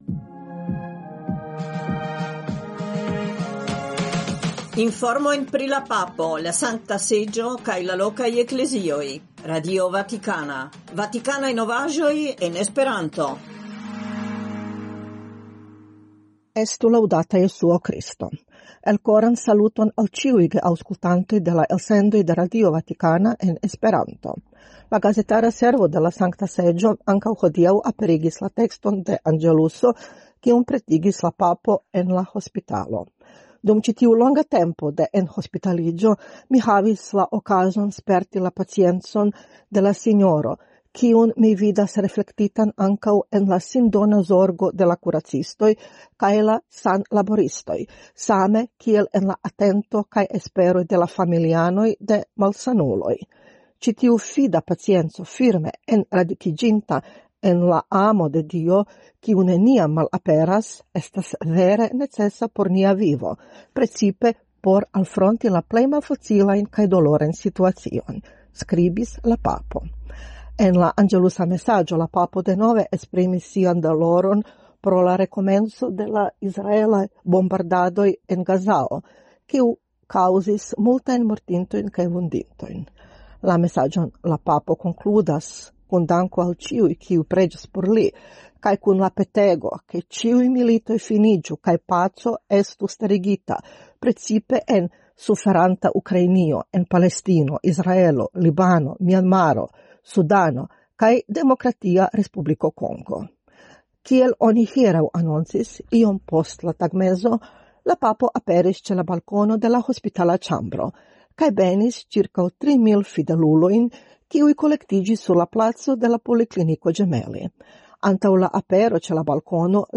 Elsendoj kun informoj el Vatikano en esperanto (trifoje semajne, merkrede, ĵaŭde kaj dimanĉe, horo 20.20 UTC). Ekde 1977 RV gastigas elsendojn en esperanto, kiuj informas pri la agado de la Papo, de la Sankta Seĝo, de la lokaj Eklezioj, donante spacon ankaŭ al internaciaj informoj, por alporti la esperon de la kredo kaj proponi interpreton de la faktoj sub la lumo de la Evangelio.